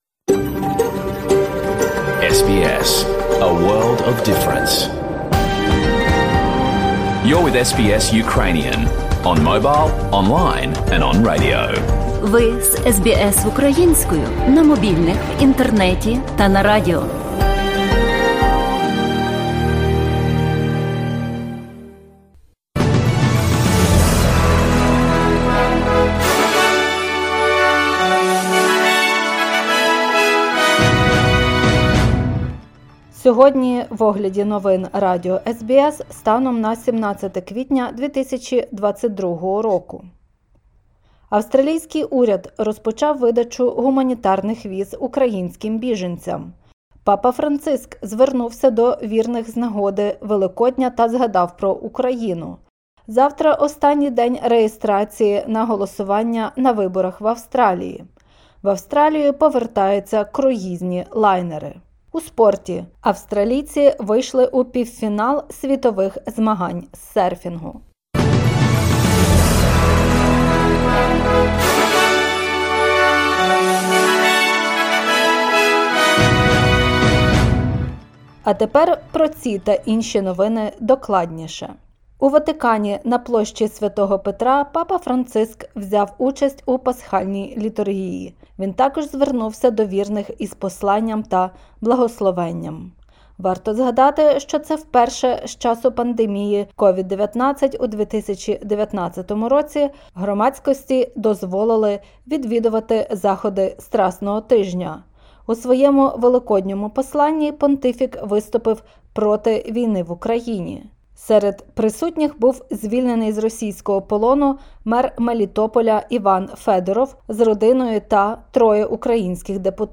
Бюлетень SBS новин українською мовою.